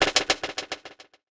TM88 MovingPerc.wav